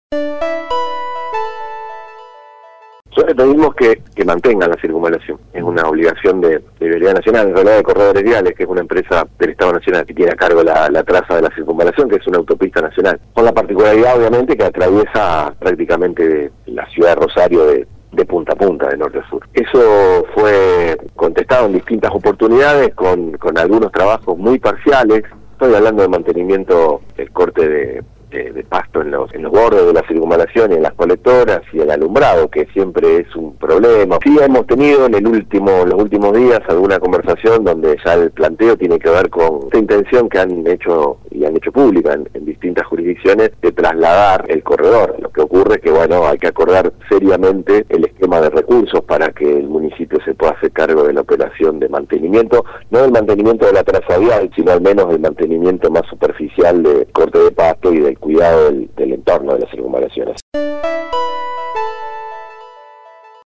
Ante este escenario es que la municipalidad de Rosario le pidió a Nación que le ceda el mantenimiento de la autopista urbana. Así lo confirmó en diálogo con el programa La barra de Casal el Secretario de Gobierno, Sebastián Chale.